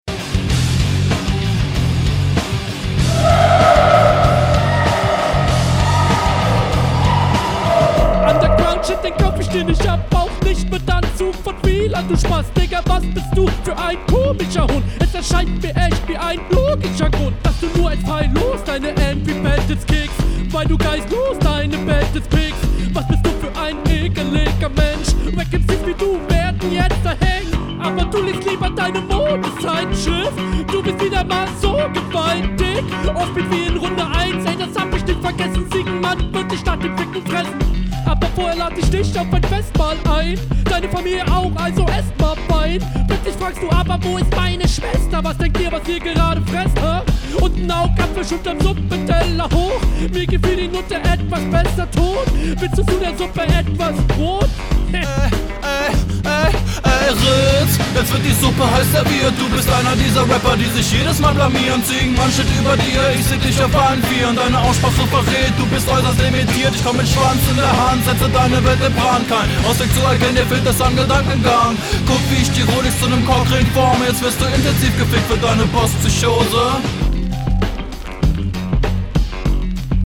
Dieses Dämonengeschrei im Intro einfach on fire.
Stimme ein bisschen zu leise.